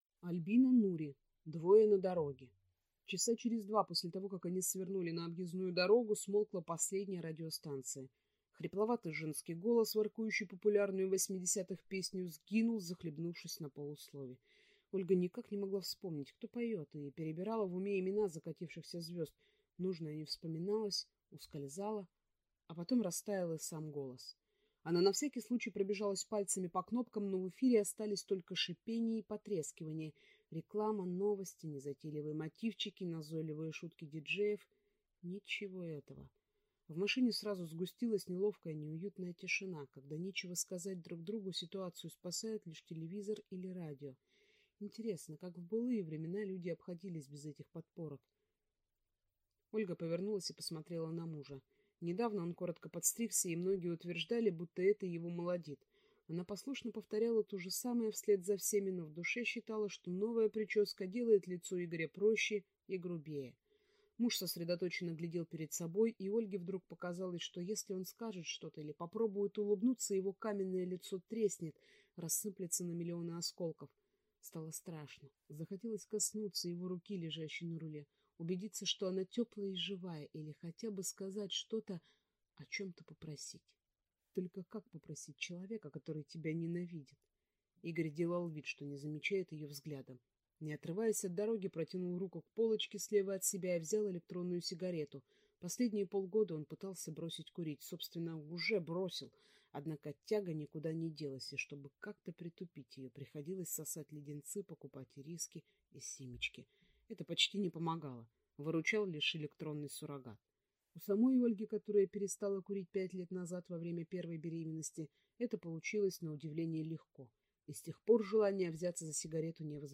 Аудиокнига Двое на дороге | Библиотека аудиокниг